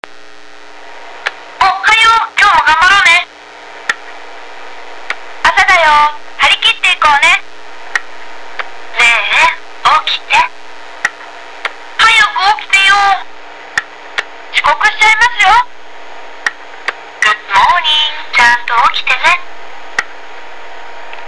6種類のセリフで起こしてくれる面白いオリジナル時計です．
ちなみにこんなセリフ． 声がうるさくて使うつもりはないけど．